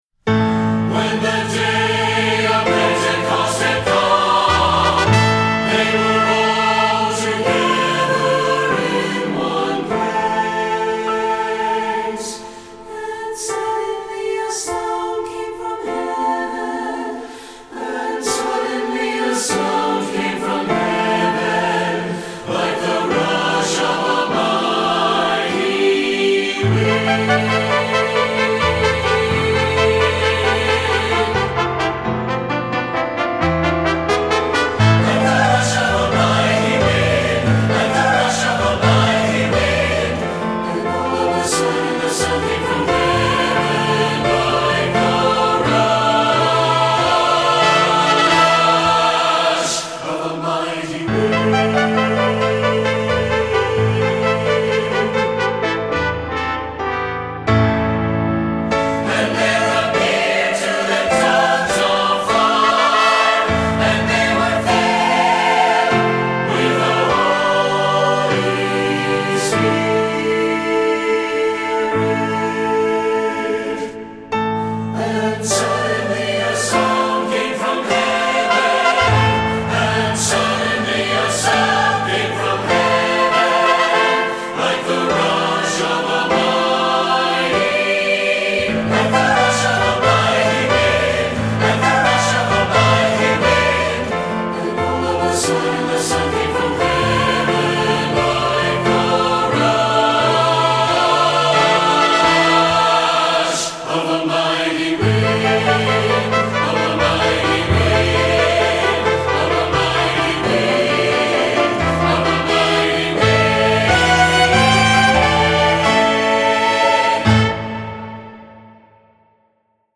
Voicing: SATB and 2 Trumpets